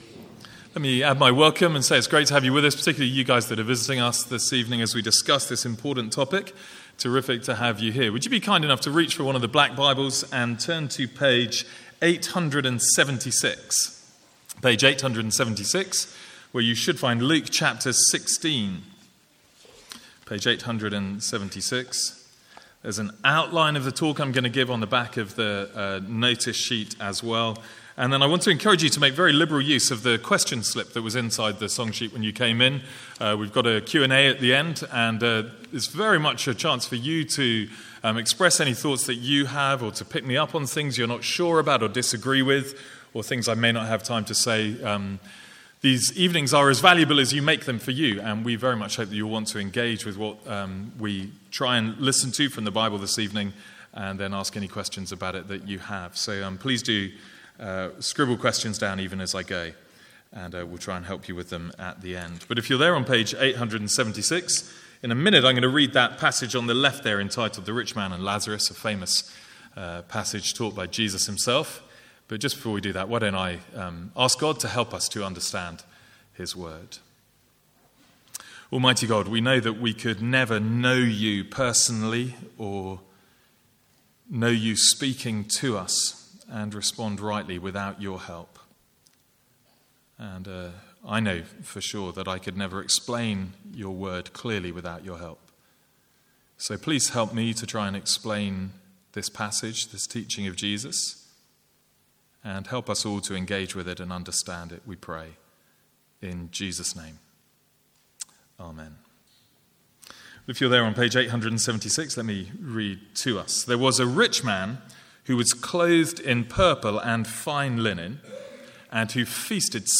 From the Sunday evening 'Tough Questions' guest series (with Q&A).